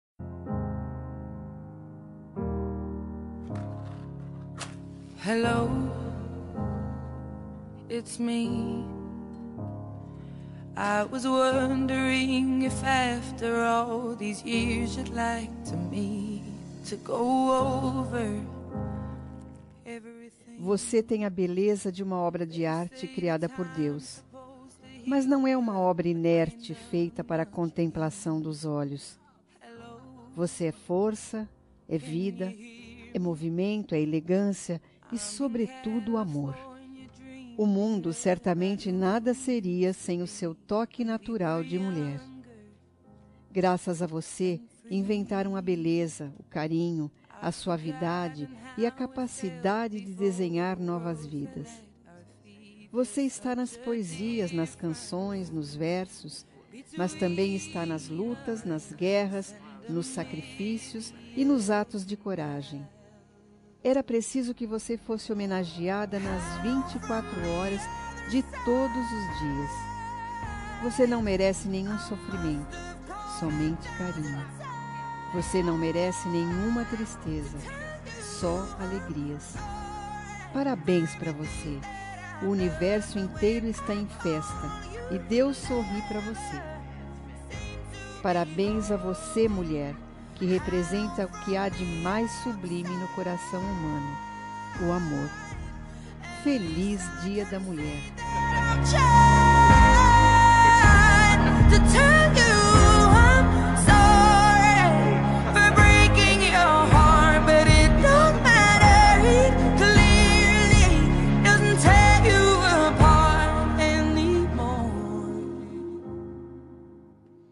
Dia das Mulheres Neutra – Voz Feminina – Cód: 5294